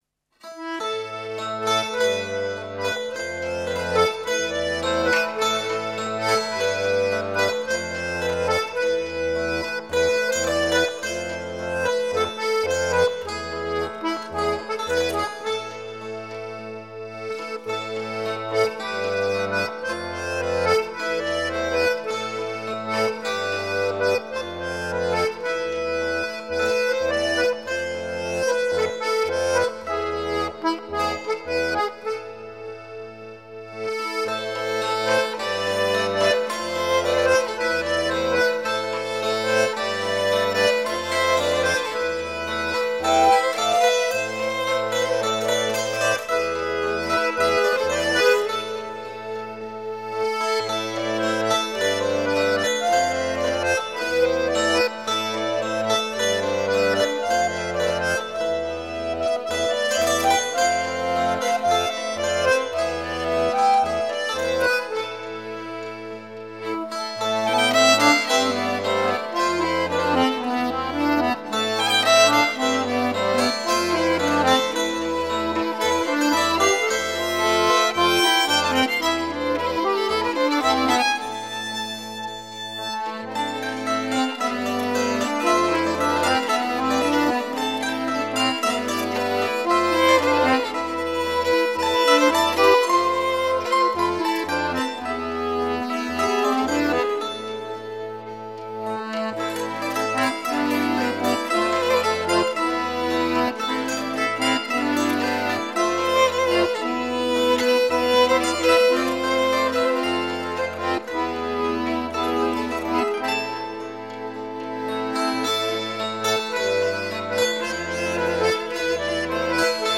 Musiques à danser
accordéon diatonique, percus
guitare, bouzouki
branle traditionnel alsacien      danse